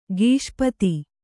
♪ gīṣpati